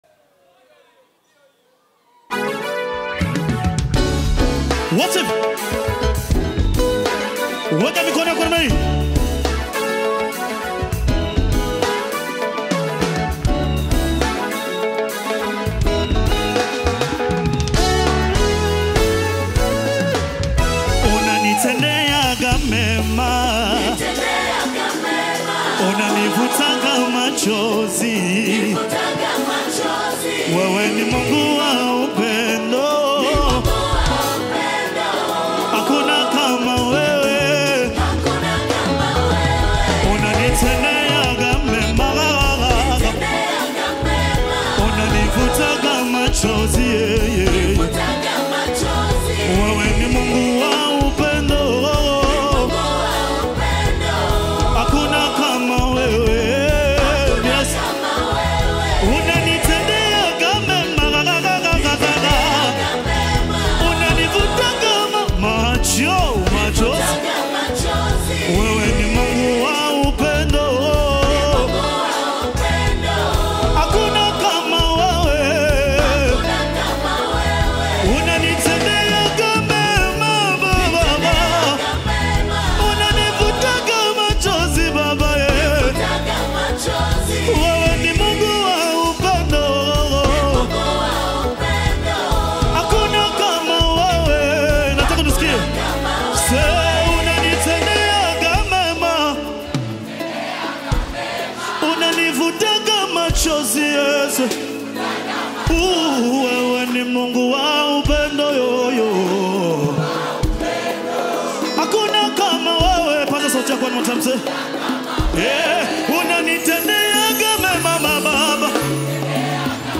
deep worship and thanksgiving gospel song